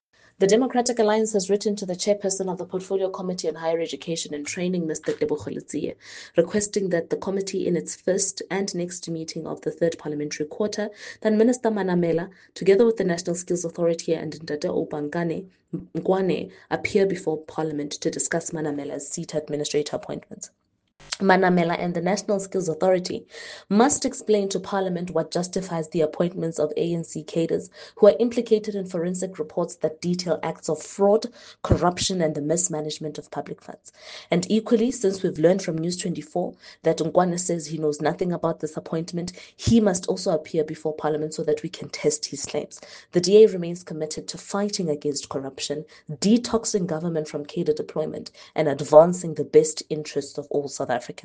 isiZulu soundbites by Karabo Khakhau MP.